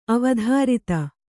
♪ avadhārita